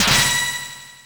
snd_weaponpull.wav